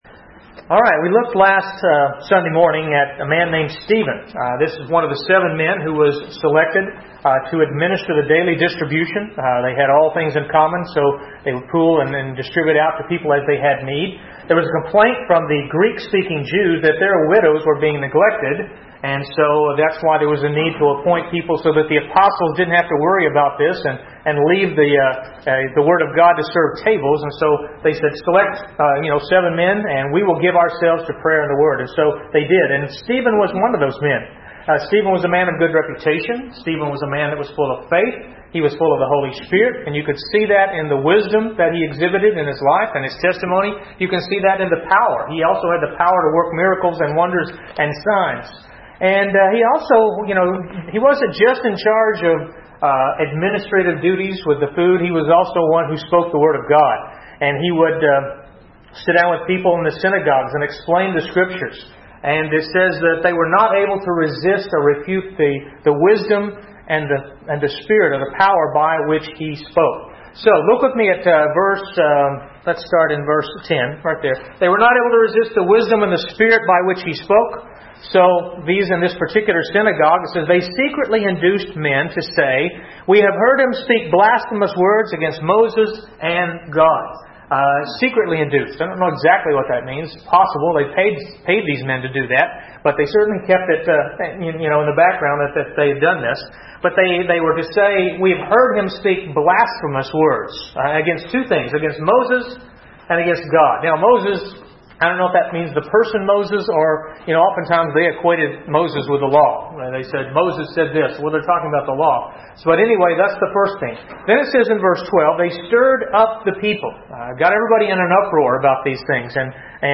A message from the series "The Book of Acts."